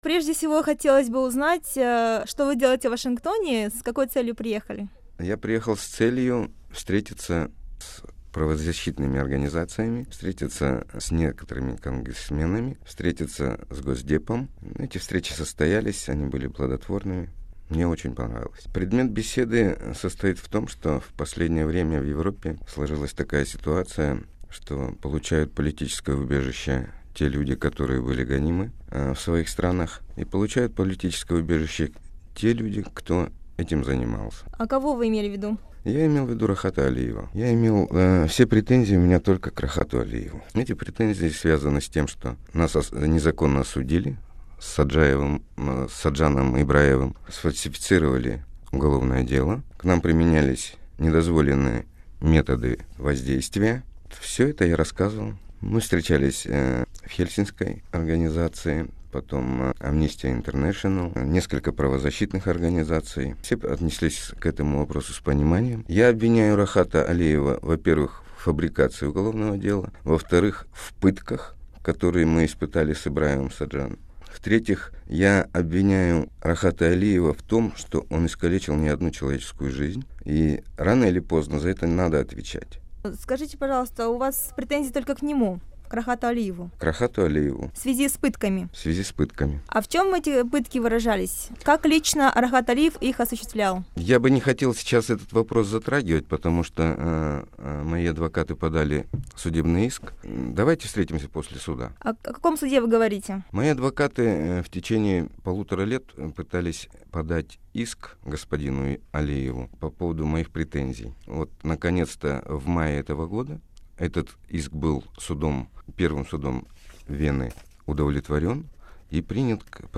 сұхбаты